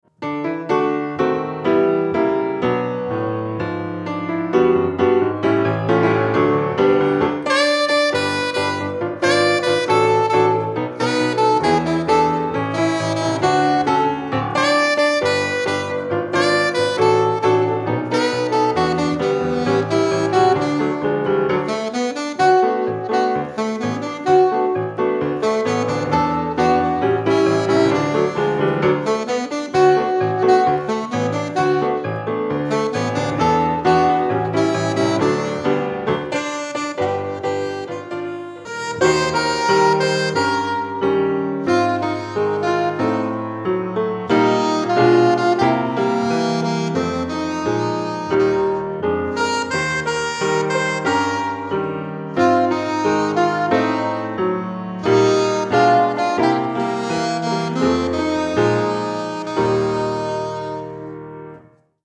Instrumentation: Melody in C + Piano